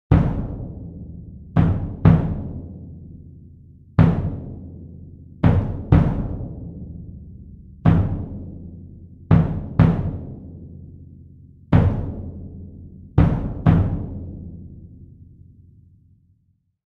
Cinematic Timpani Sound Effect
Description: Cinematic timpani sound effect. A simple, dramatic timpani rhythm. An excellent sound to announce or maintain tension in a game, TikTok or YouTube video, or film. Deep dramatic percussion.
Cinematic-timpani-sound-effect.mp3